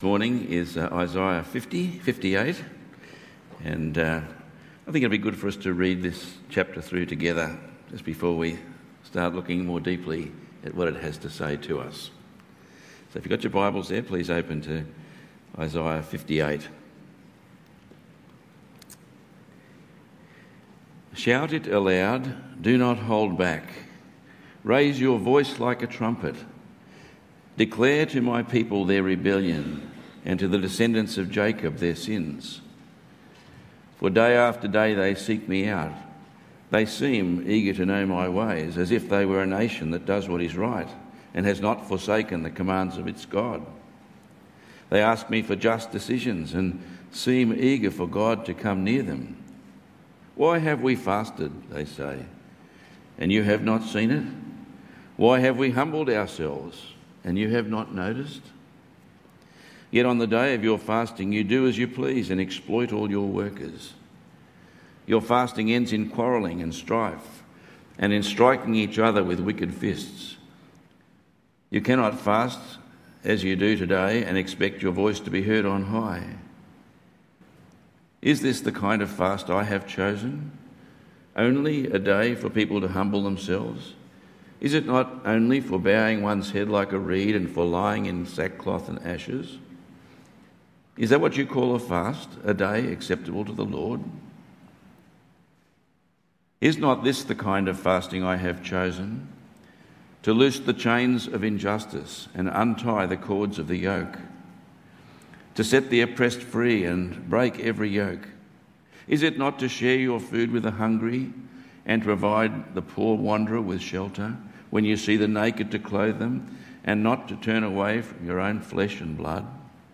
Tagged with Sunday Morning